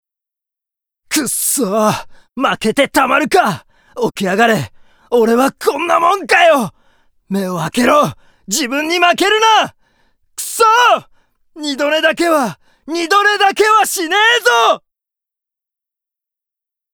ボイスサンプル ＜青年＞
3_青年.mp3